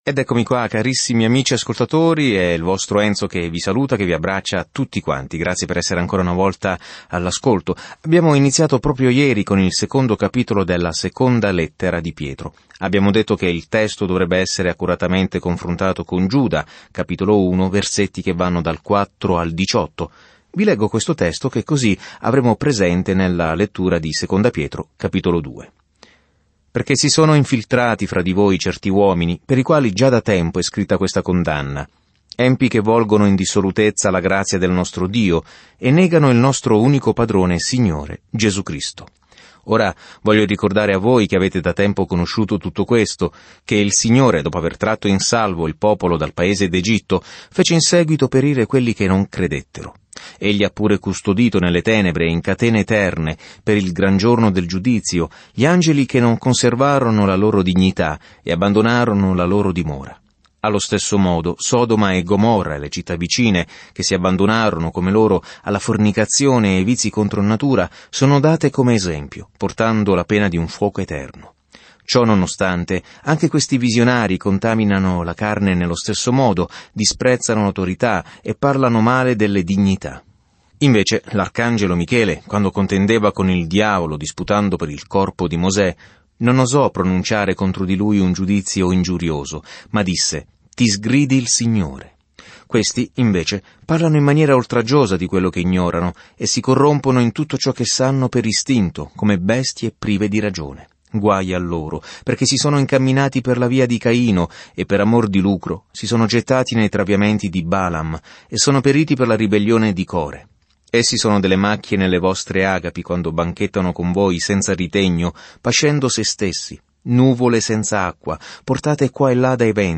Viaggia ogni giorno attraverso 2 Pietro mentre ascolti lo studio audio e leggi versetti selezionati della parola di Dio.